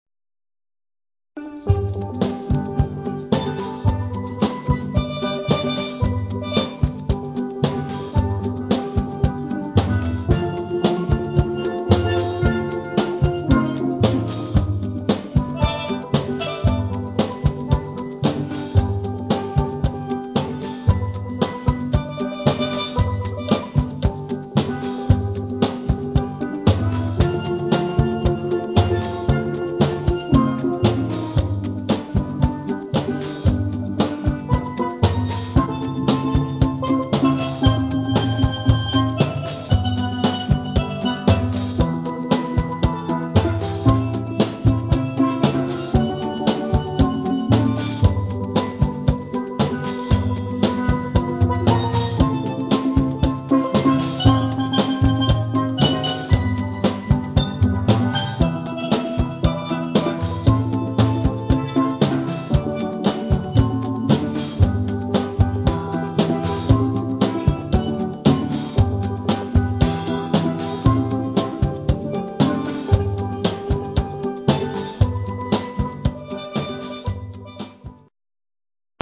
• Authentic Caribbean musicians and music
• Perfect to evoke sunny carnival ambience
• Versatile traditional steelpan ensemble